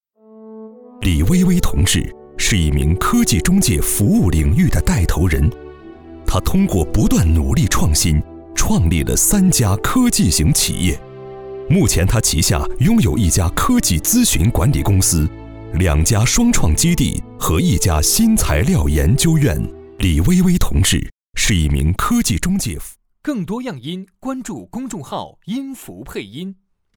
男2号配音师
专题片-男2-人物.mp3